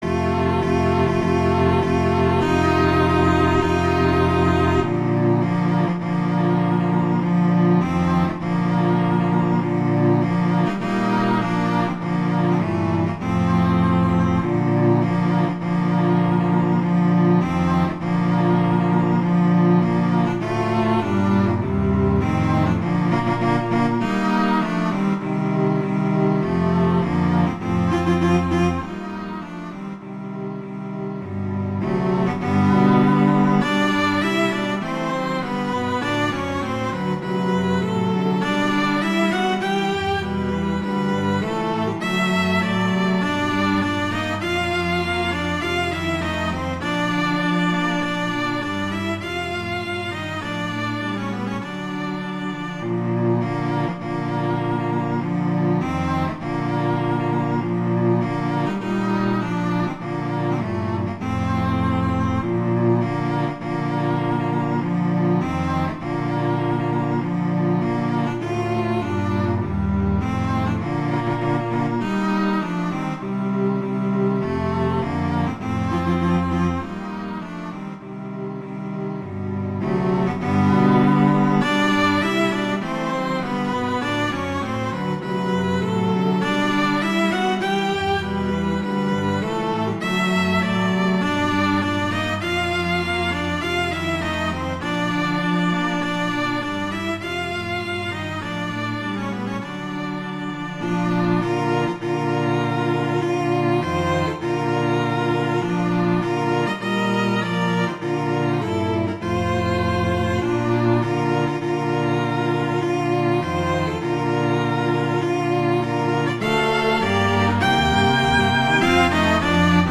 arrangements for four cellos
wedding, traditional, classical, festival, love, french